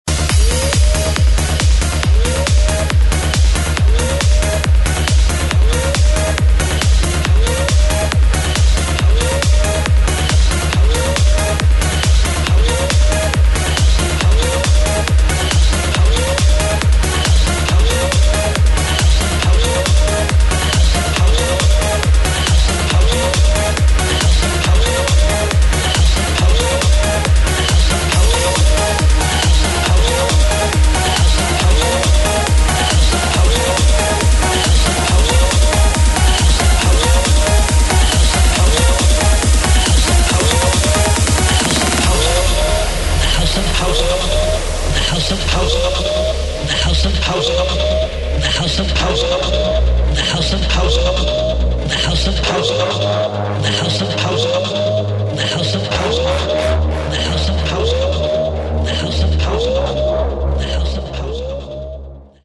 Género: Electronic
Estilo: Hard TranceTrance